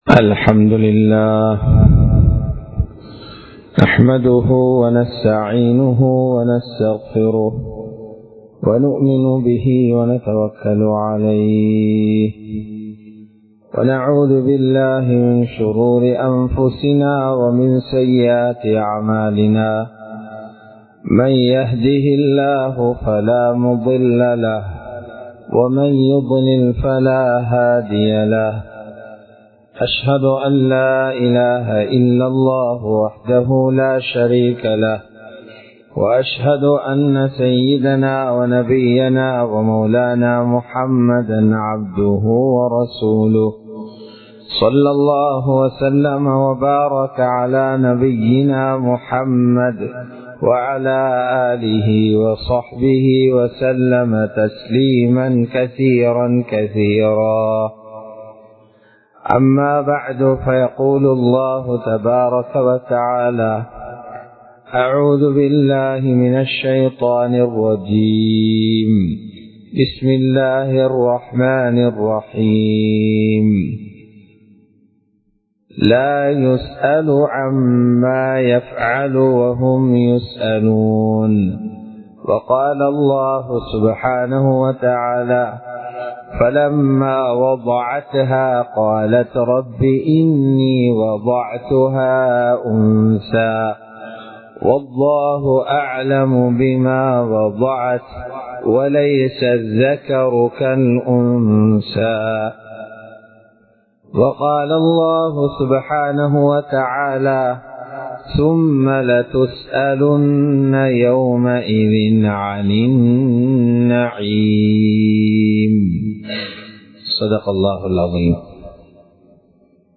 பெண் பிள்ளைகளுக்கு கொடுக்க வேண்டியவைகள் | Audio Bayans | All Ceylon Muslim Youth Community | Addalaichenai
Colombo 06, Mayura Place, Muhiyadeen Jumua Masjith